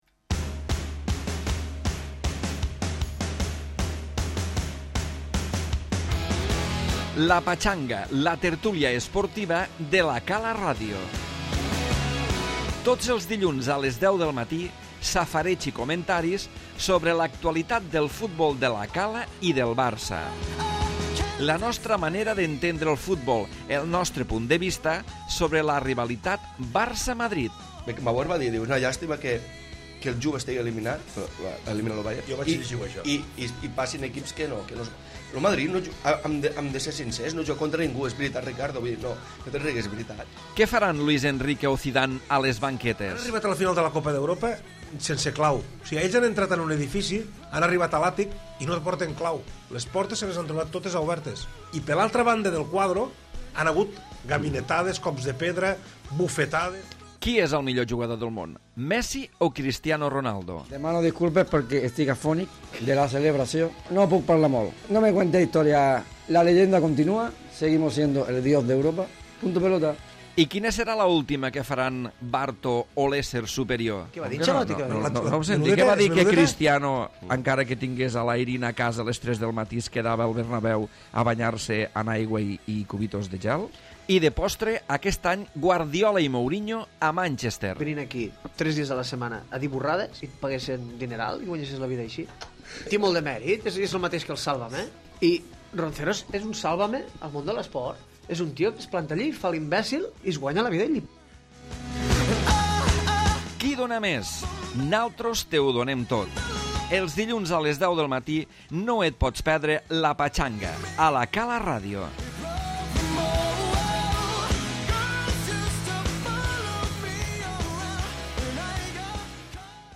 Primer programa de la temporada de la tertúlia esportiva i anàlisi del partit que va enfrontar als primers equips de La Cala i l'Aldeana. A més, tots els comentaris de la derrota del Barça a Vigo i de l'empat del Madrid a casa amb l'Eibar.